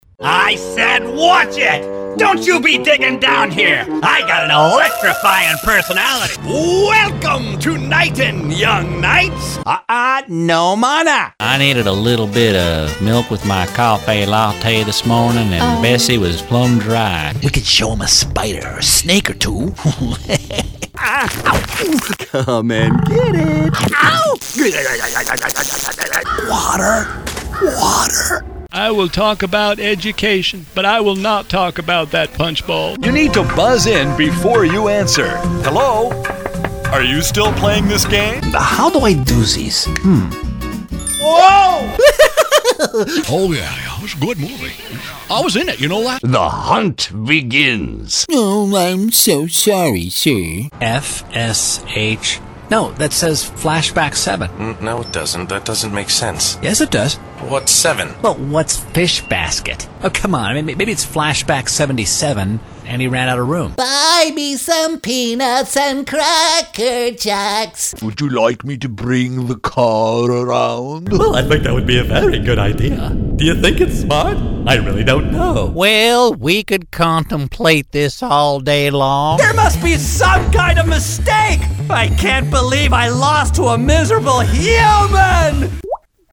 Demo
Young Adult, Adult, Mature Adult
british rp | natural
standard us | natural
ANIMATION 🎬